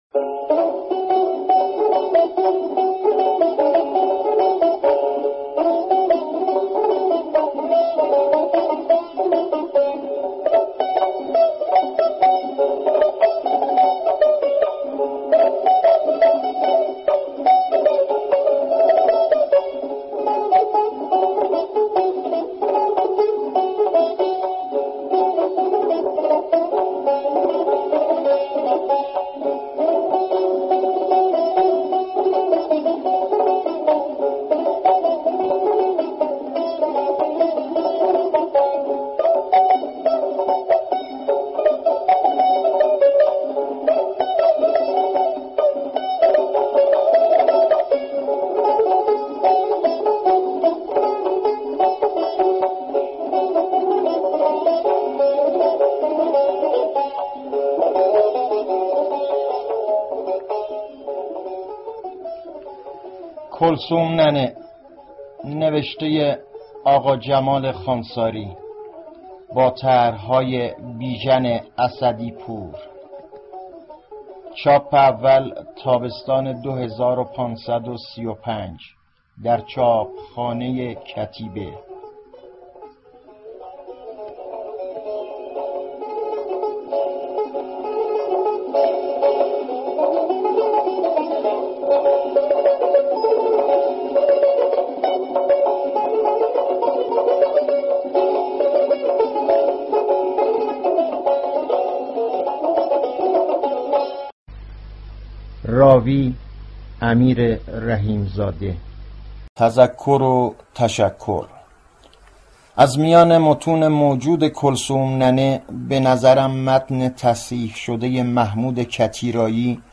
کتاب صوتی کلثوم ننه